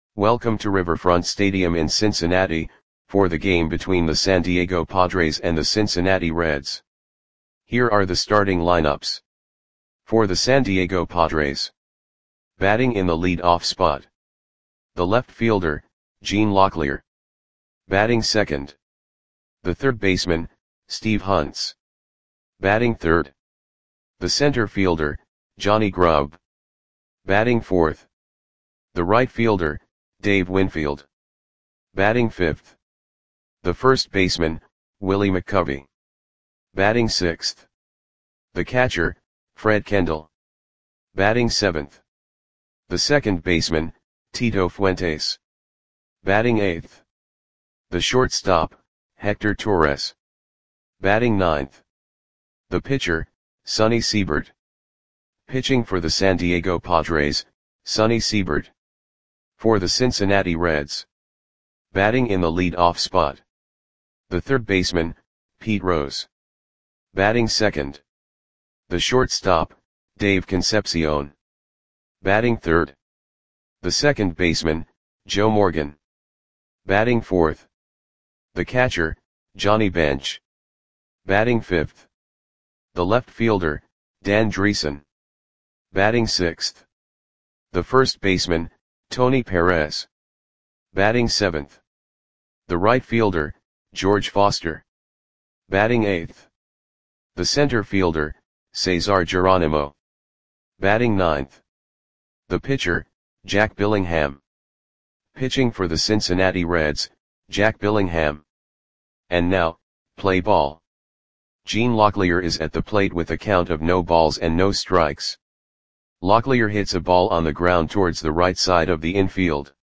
Audio Play-by-Play for Cincinnati Reds on May 6, 1975
Click the button below to listen to the audio play-by-play.